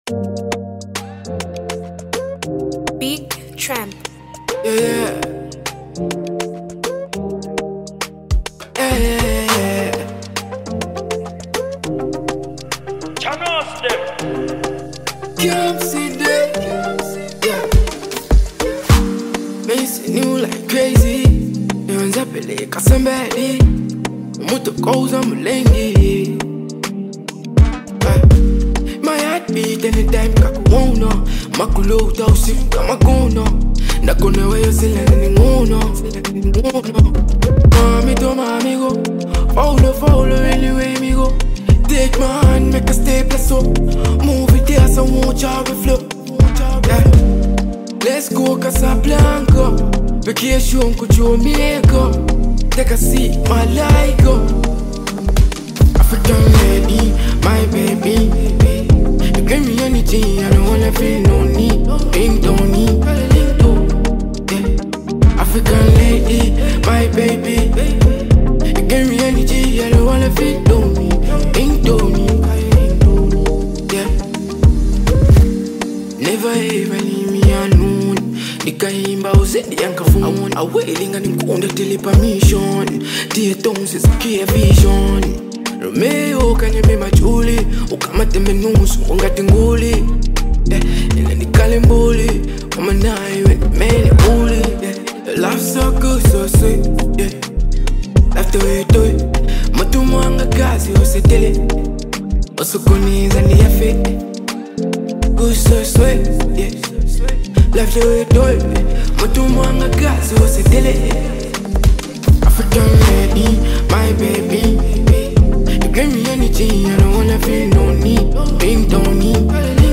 vibrant dancehall track
signature energetic beats and catchy hooks